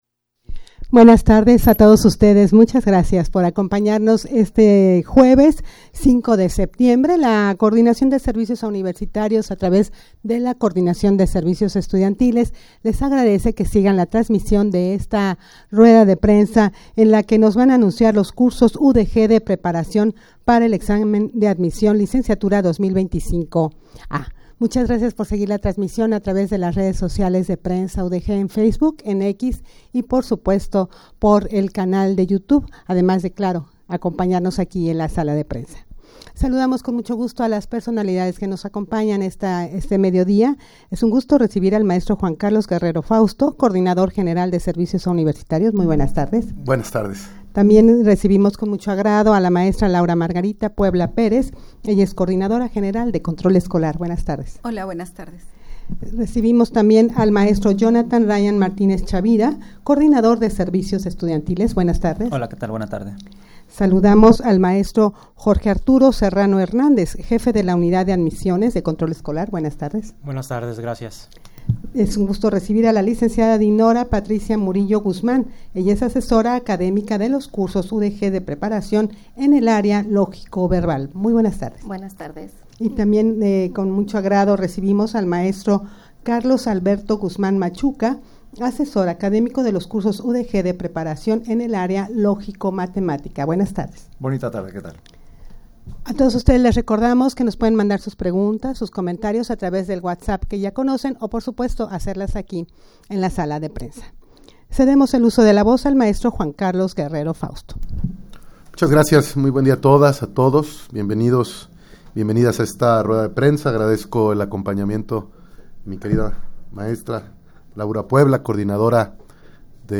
rueda-de-prensa-para-anunciar-cursosudg-de-preparacion-para-el-examen-de-admision-licenciatura-2025-a.mp3